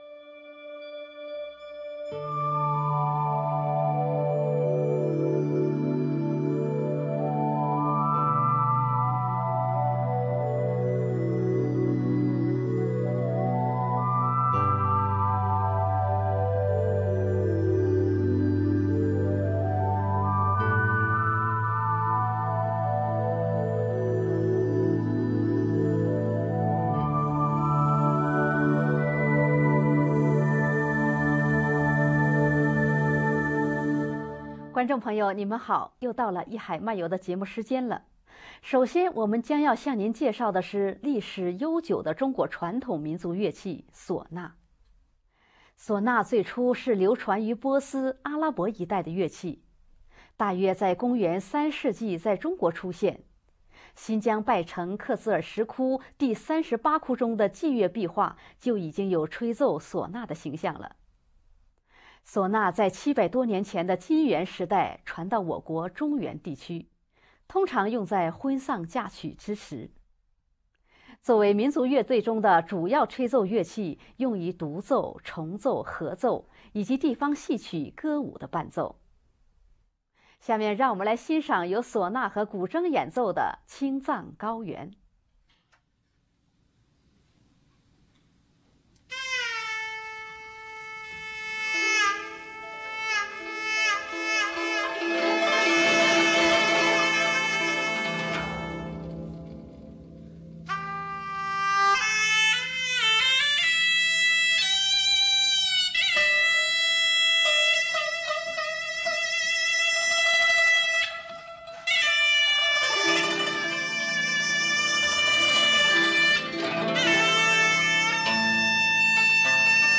嗩吶和古箏演奏
陝西民族特色的